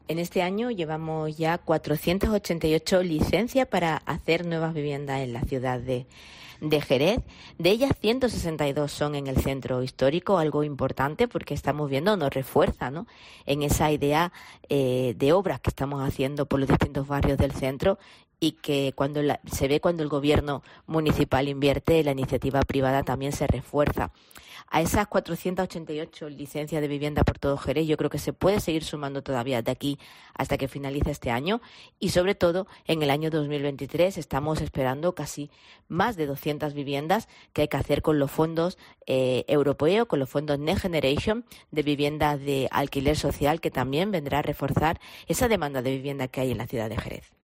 Escucha aquí a Mamen Sánchez, la alcaldesa de Jerez